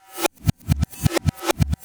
drums03.wav